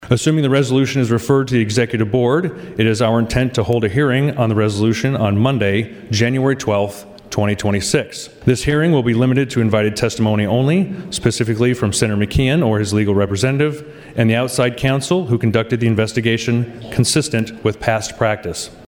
HANSEN ASKED FOR A SPECIAL HEARING ON THE MATTER NEXT WEEK: